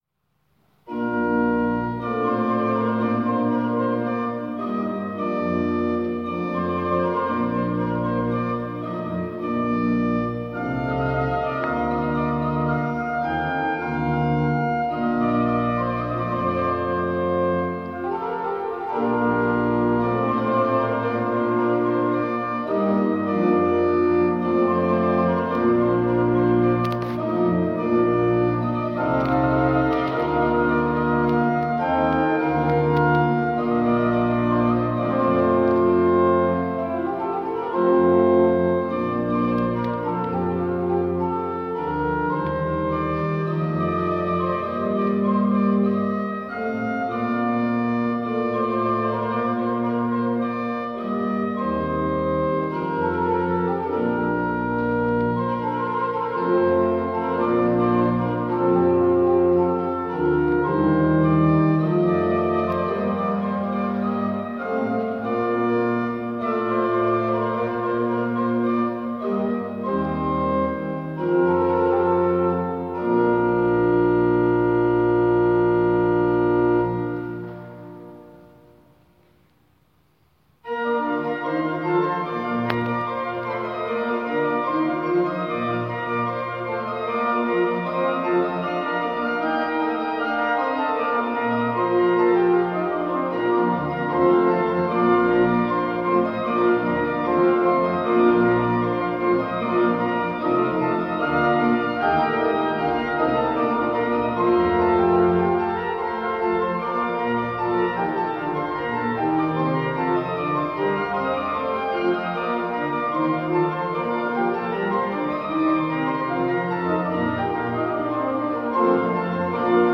Lassen Sie sich einfangen von unserer Orgelmusik...